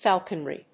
• / fˈælk(ə)nri(米国英語)
• / fˈɔː(l)knri(英国英語)